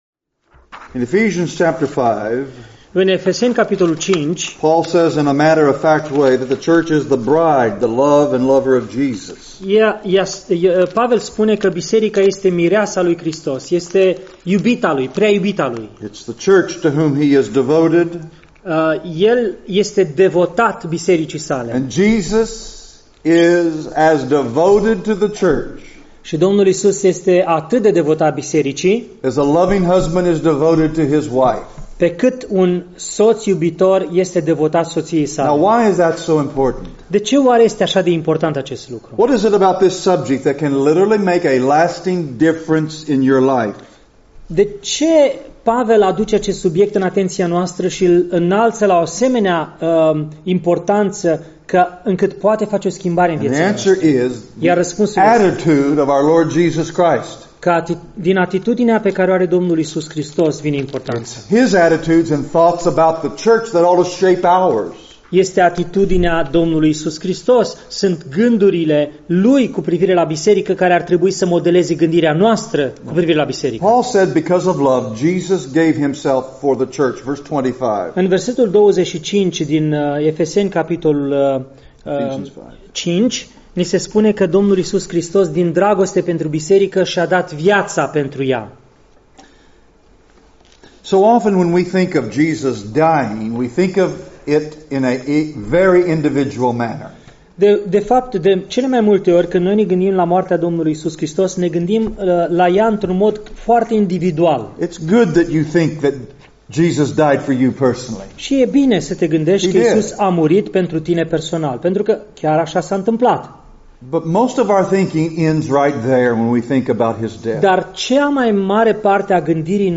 Predici Complete